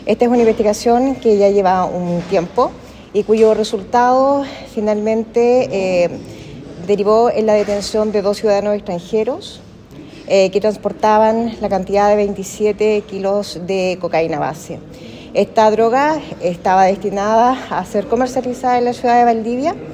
La fiscal jefe de Valdivia, Alejandra Anabalón, confirmó que ambos salieron desde Santiago y al ingresar a la región de O’Higgins, fueron controlados y detenidos, al portar más de 27 kilos de pasta base.
cuna-droga-fiscal.mp3